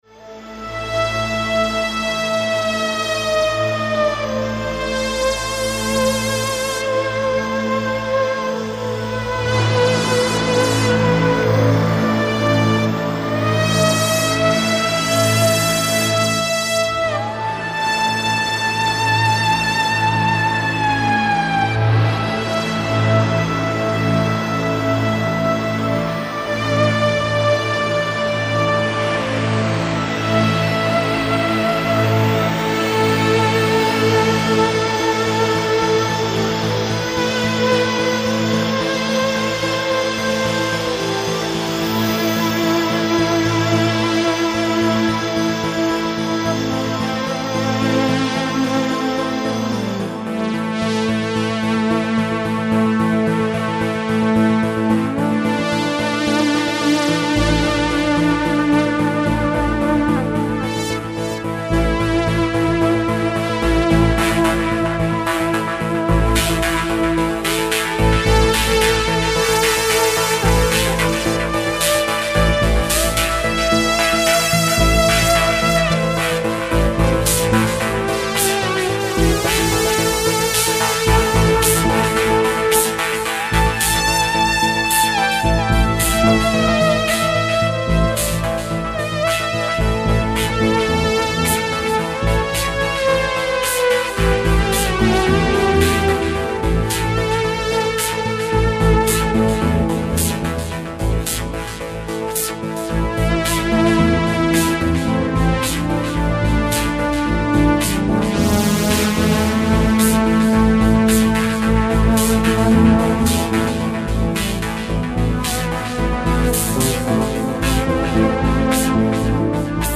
w sali teatru j.w. wystąpili
Po za tym  nasza muzyka była grana na żywo